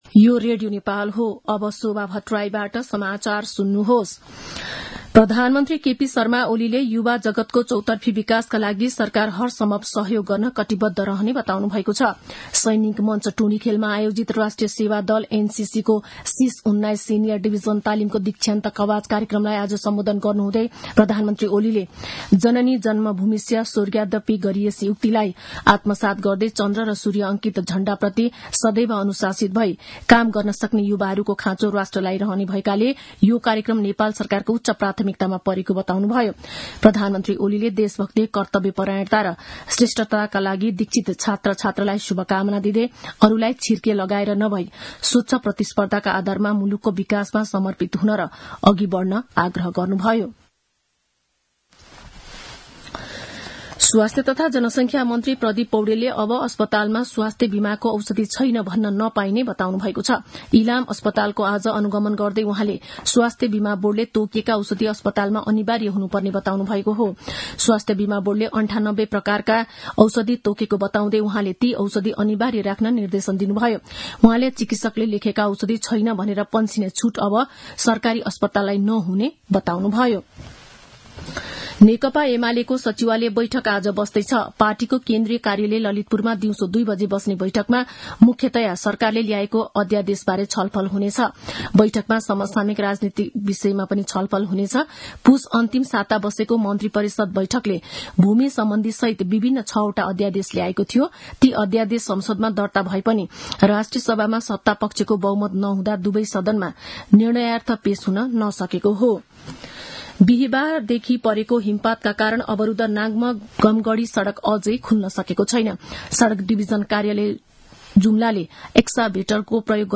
दिउँसो १ बजेको नेपाली समाचार : १९ फागुन , २०८१
1-pm-news-.mp3